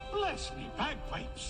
bless-me-bagpipes.mp3